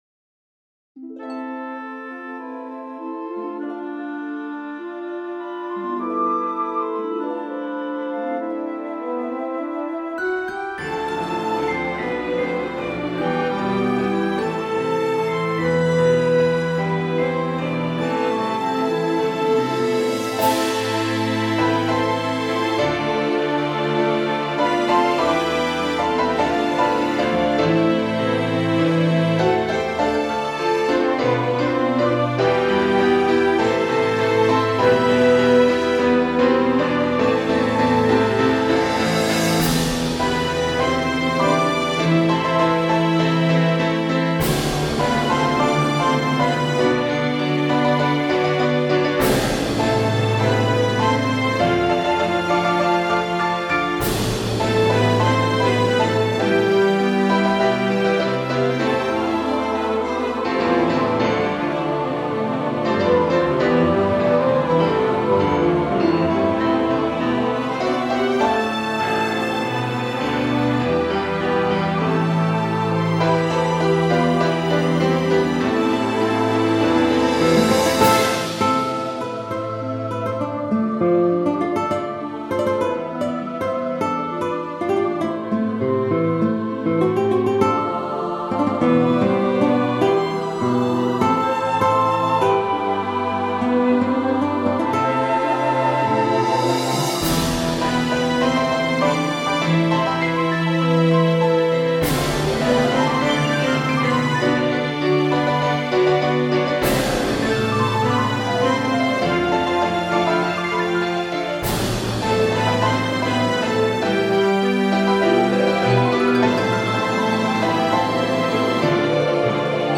I wanted to write a romantic piece.